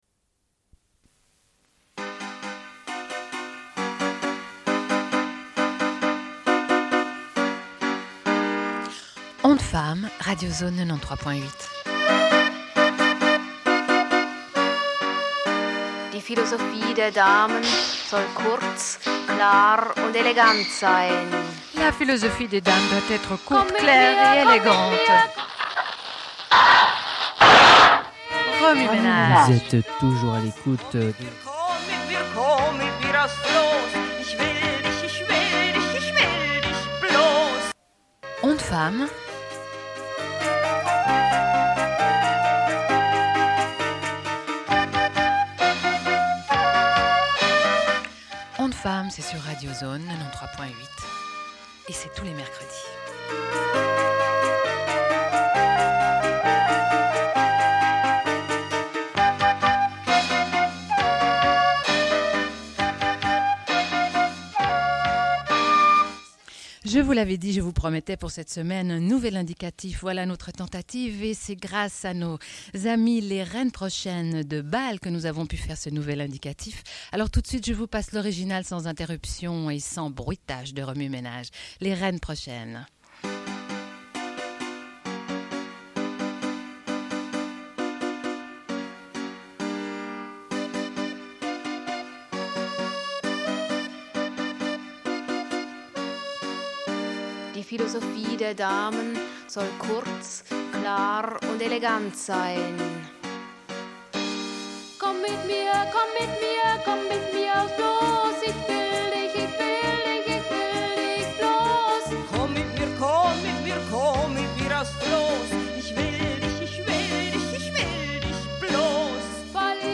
Une cassette audio, face A30:46